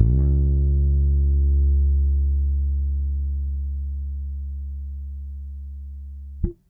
808s
bass5.wav